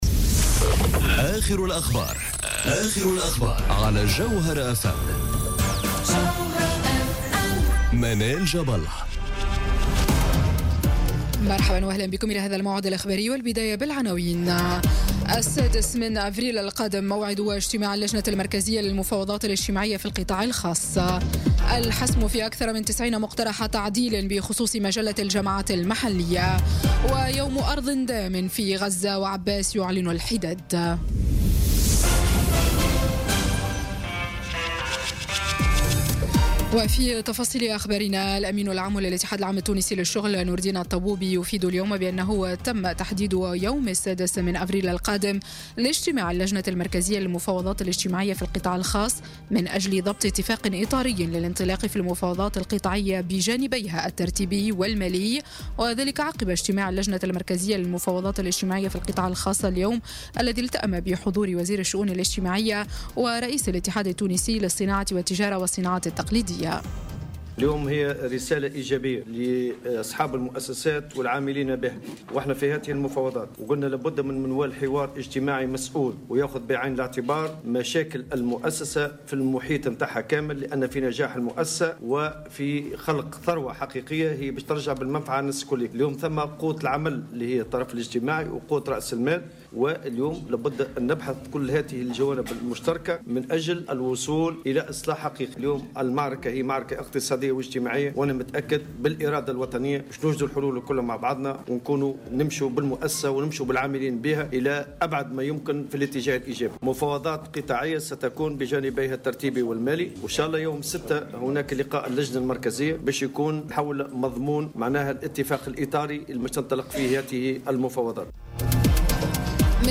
نشرة أخبار السابعة مساءً ليوم الجمعة 30 مارس 2018